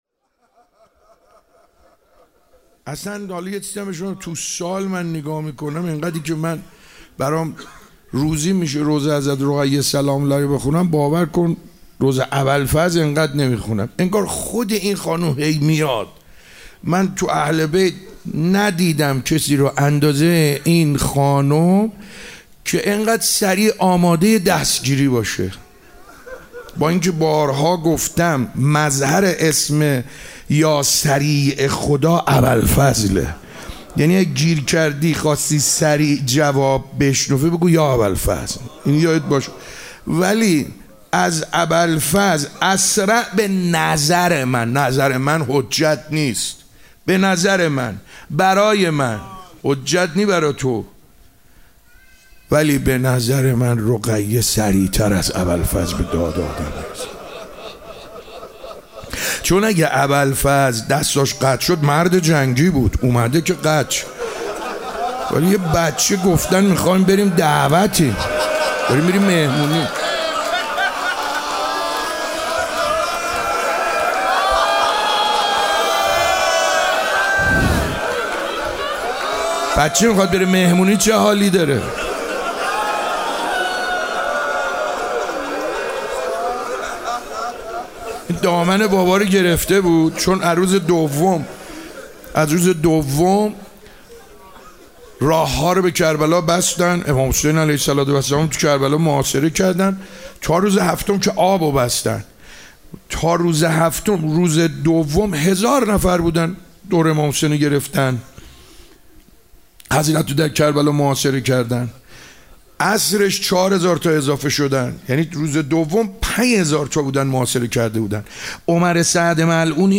روضه‌ حضرت رقیه (س)
در سومین روز از مراسم عزاداری حضرت سیدالشهداء (ع) که با حضور پرشور مردم در مهدیه امام حسن مجتبی (ع) برگزار شد، به روضه‌خوانی درباره حضرت رقیه